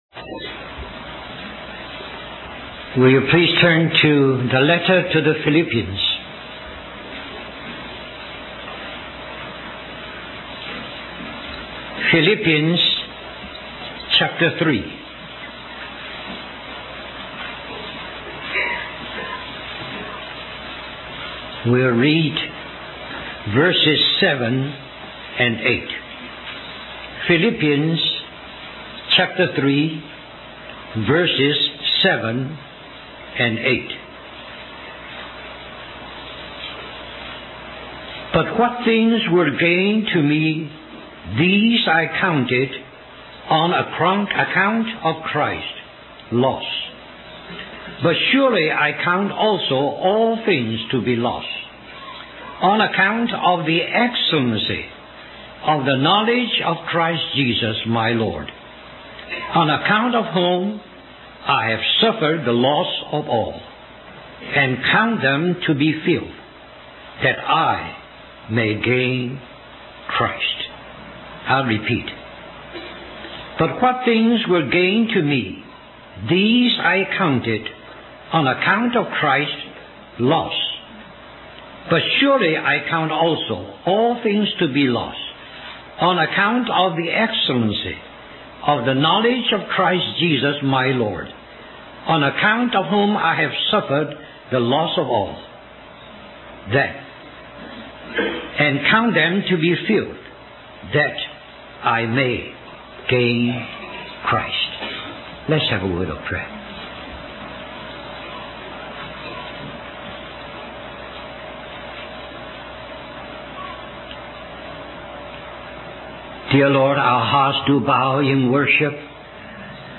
A collection of Christ focused messages published by the Christian Testimony Ministry in Richmond, VA.
1998 Harvey Cedars Conference Stream or download mp3 Summary This message is also printed in booklet form under the title